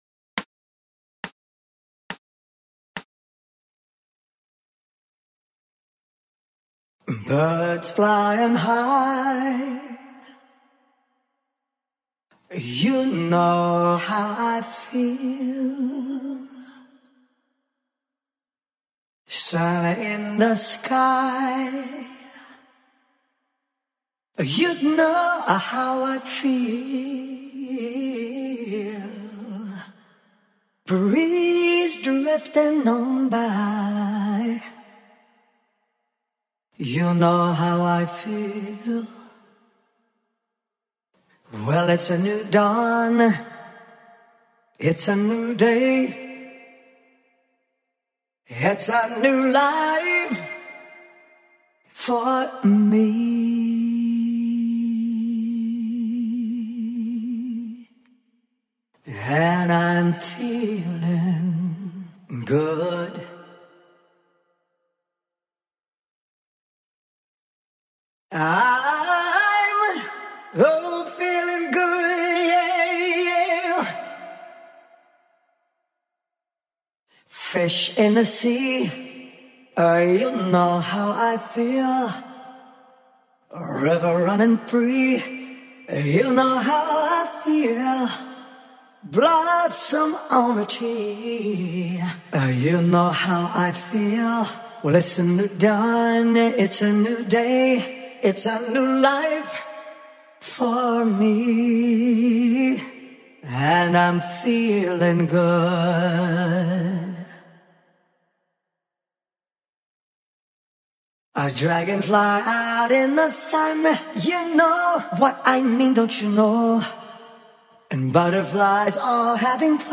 FEELING-GOOD-Voz-principal.mp3